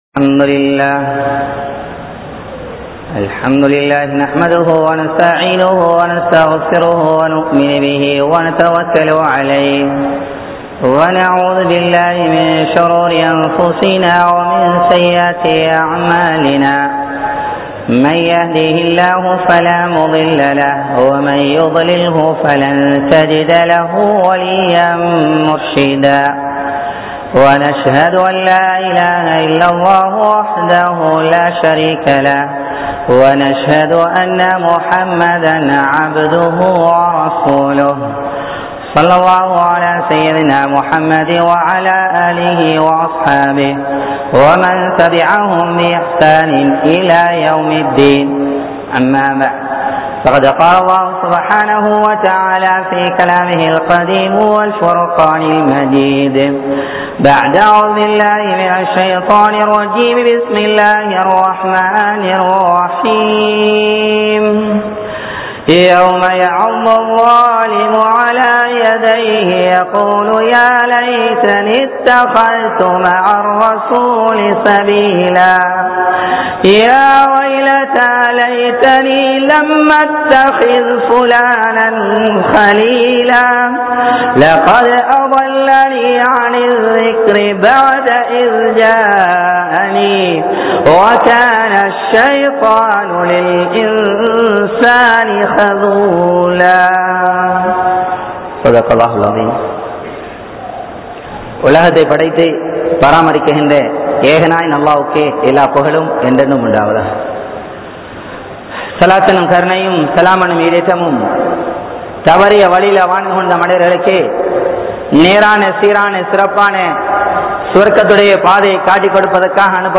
Indraya Vaalifarhalum Zina`vum(இன்றைய வாலிபர்களும் விபச்சாரமும்) | Audio Bayans | All Ceylon Muslim Youth Community | Addalaichenai
Meera Masjith(Therupalli)